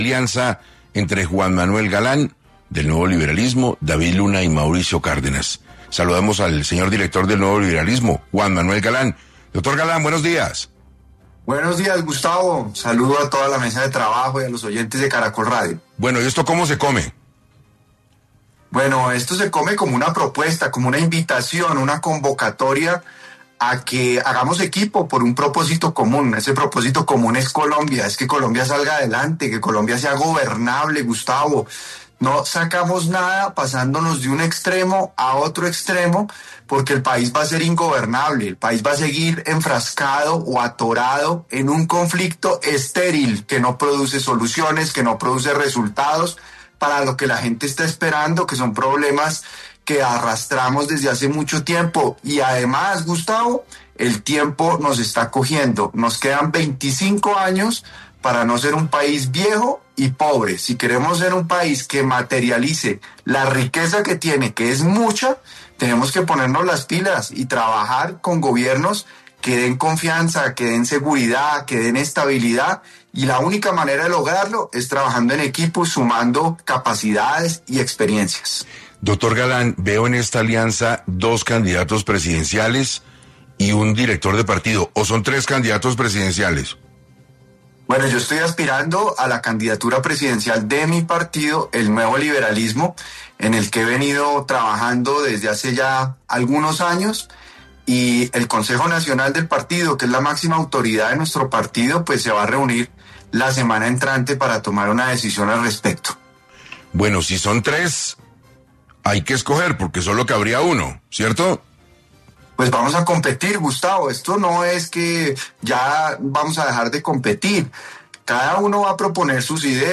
En entrevista con 6AM de Caracol Radio, Galán enfatizó que el país no puede permitirse pasar de un extremo a otro, ya que esto lo mantendría en un conflicto estéril que no produce soluciones ni resultados para los problemas que la gente espera resolver.